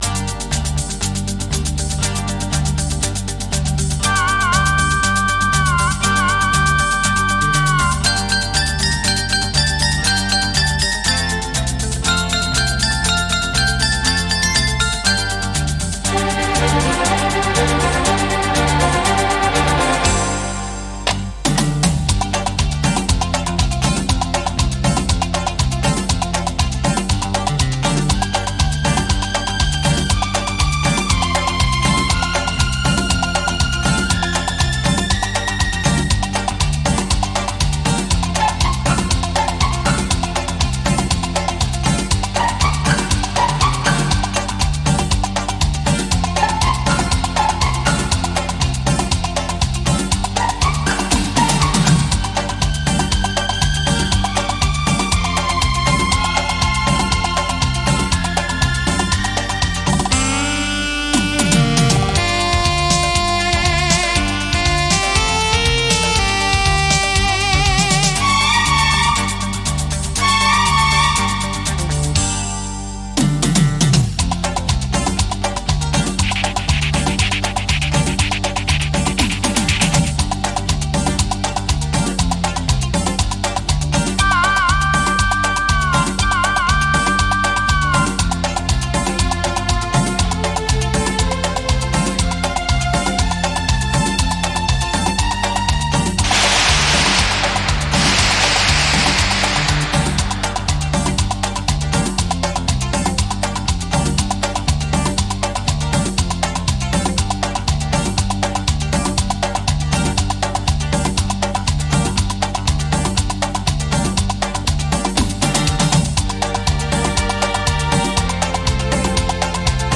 Artist: Instrumental,